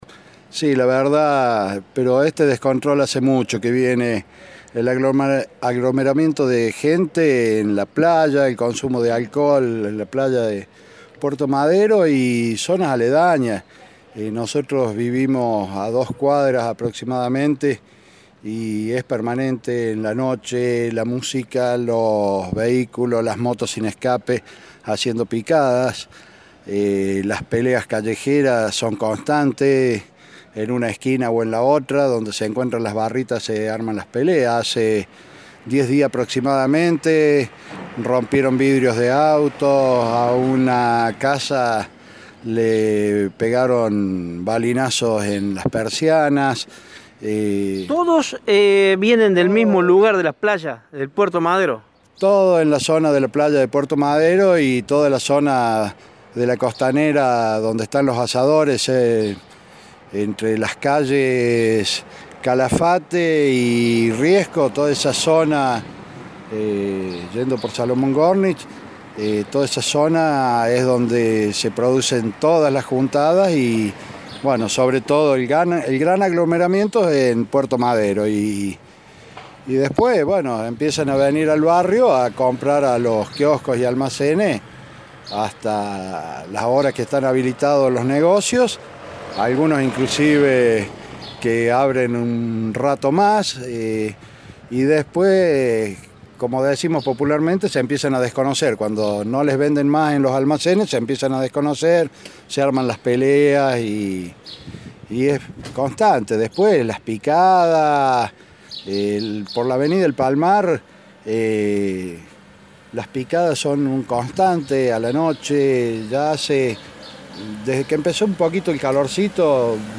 Un vecino del barrio Mariano Moreno, contó que en el lugar no había ningún tipo de control.